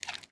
Minecraft Version Minecraft Version snapshot Latest Release | Latest Snapshot snapshot / assets / minecraft / sounds / mob / panda / eat4.ogg Compare With Compare With Latest Release | Latest Snapshot
eat4.ogg